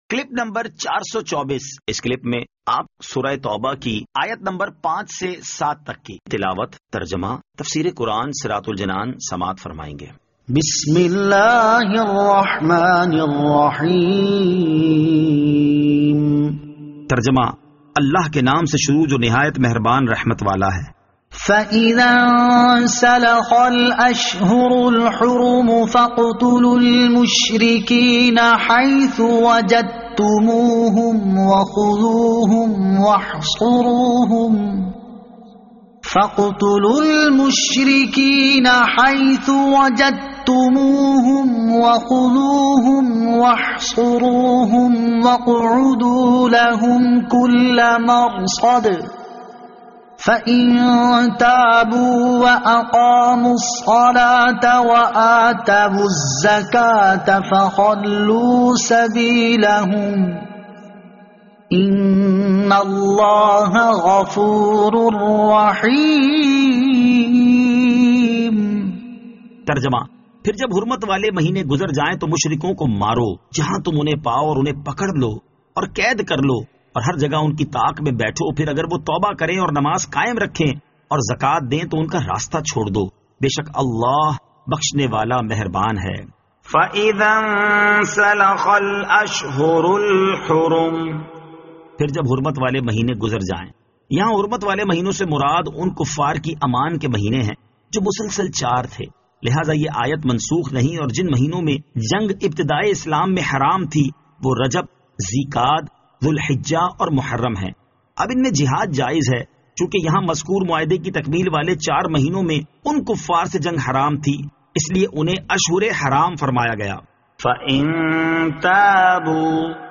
Surah At-Tawbah Ayat 05 To 07 Tilawat , Tarjama , Tafseer